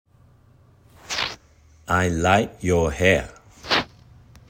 より自然なカタカナ アイ　ライク　ヨア　ヘア
「Like」の語尾を言わない、もしくは消え入るように。「your」はよく聞くと「ユ」よりも「ヨ」よりですね。